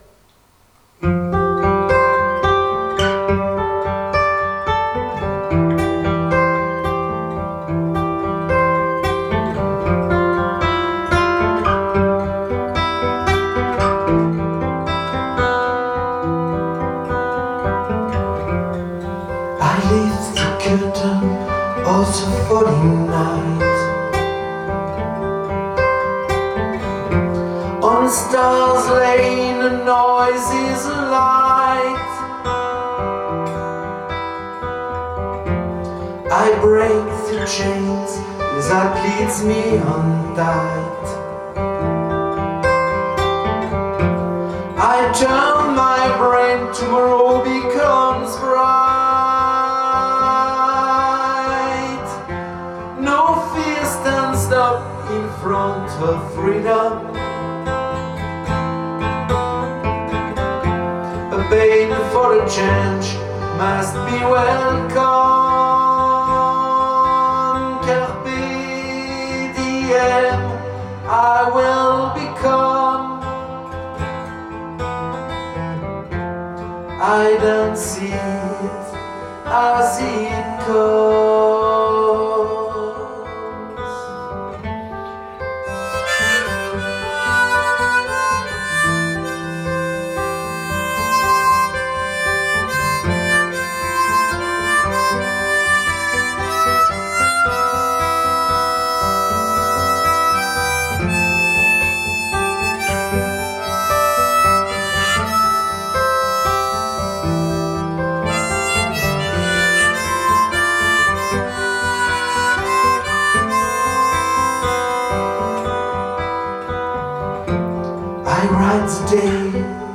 Slow rumba dans le monde du rêve éveillé